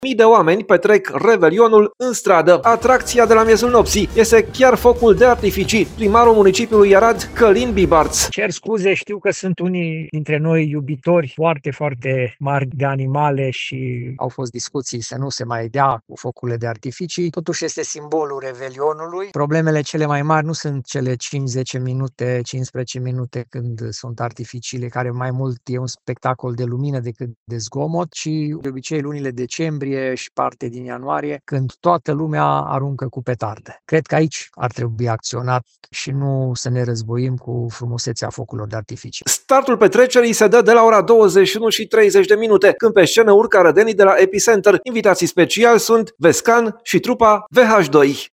Primarul municipiului Arad, Călin Bibarț: „Problemele cele mai mari nu sunt cele cinci-zece minute, 15 minute când sunt artificiile”